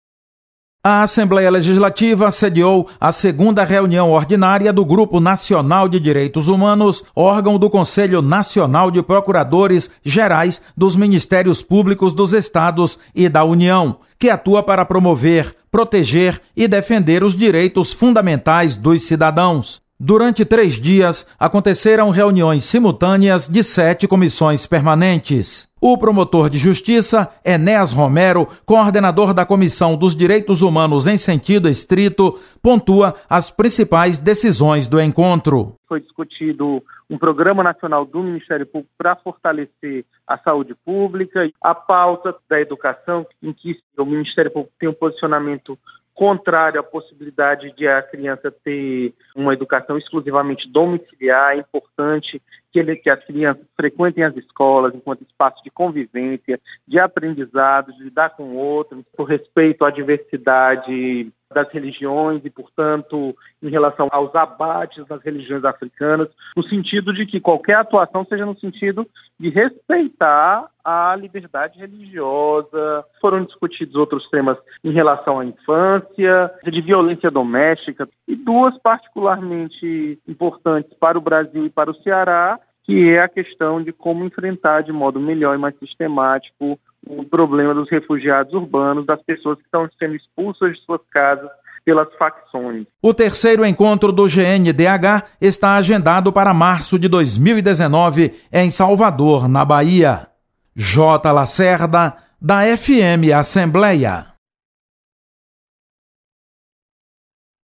Encontro Nacional do Ministério Público reforça preocupação com minorias. Repórter